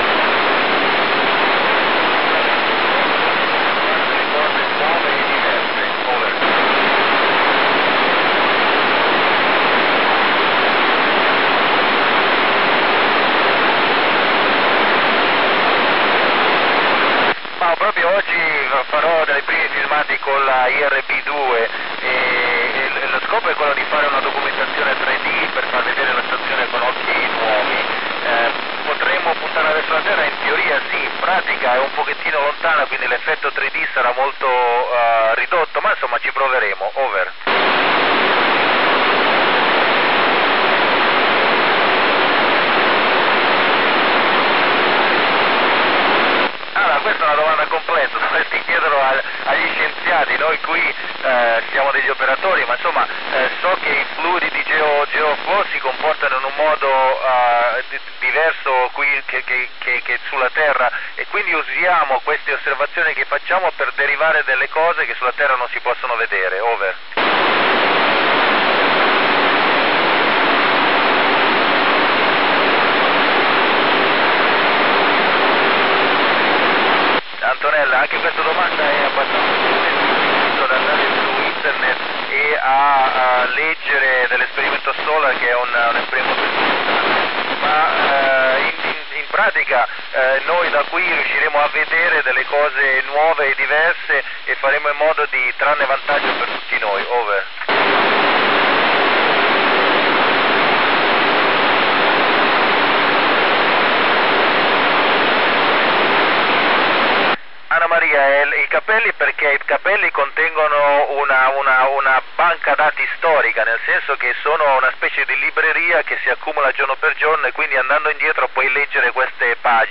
Paolo Nespoli's voice heard over Rome, Italy during the school contact with University of Bari. The signal was very strong captured with Icom IC-R7000